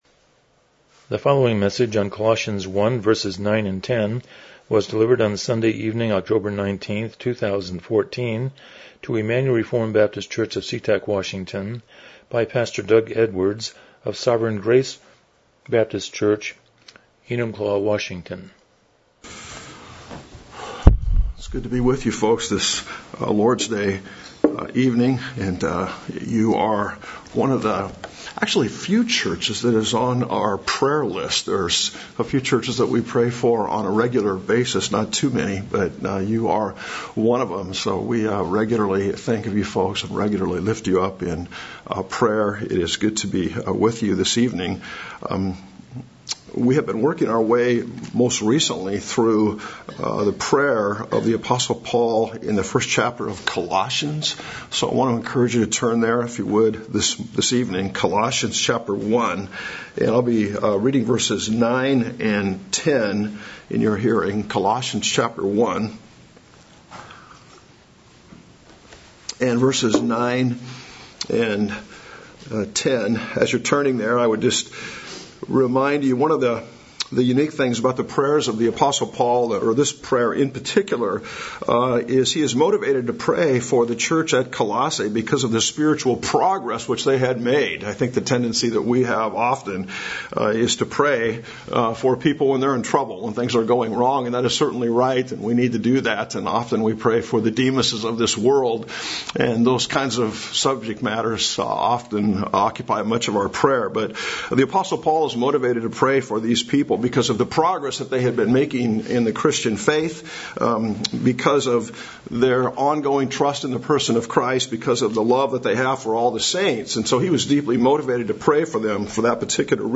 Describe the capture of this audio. Colossians 1:9-10 Service Type: Evening Worship « Walking on Water